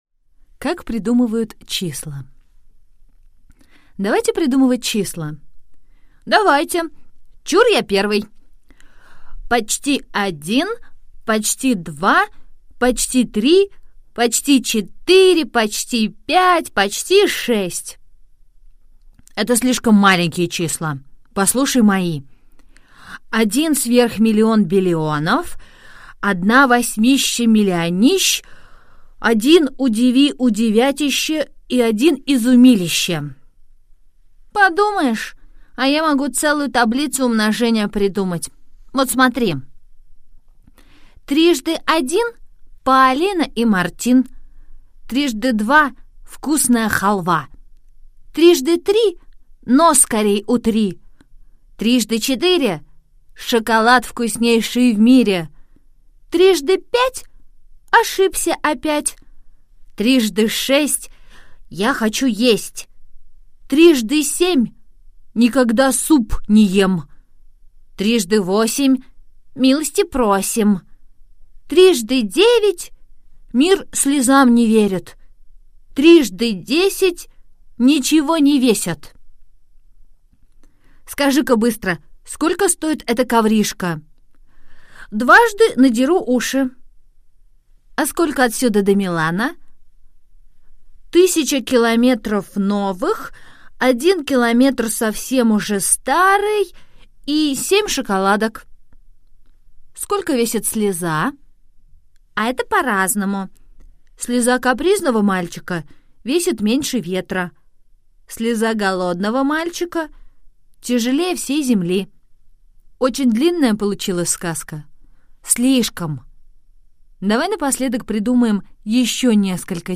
Аудиосказка «Как придумывают числа»